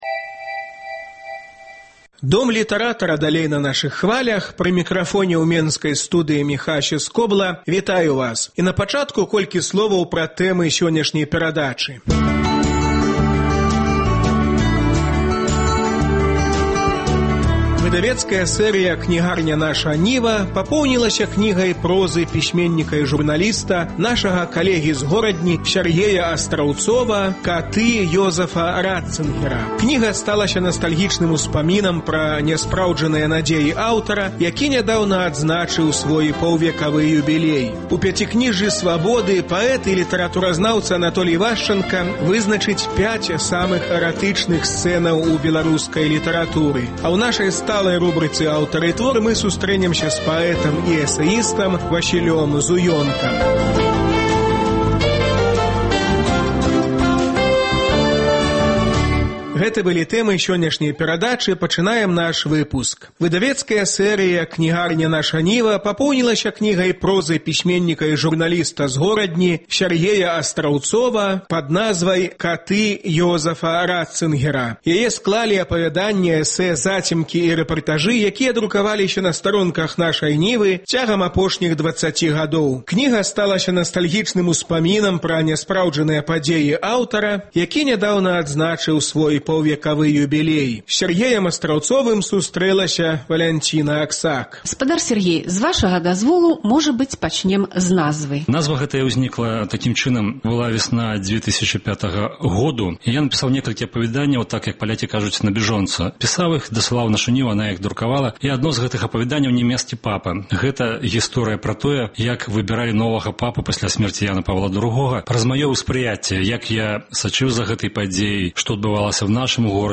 Размова зь пісьменьнікам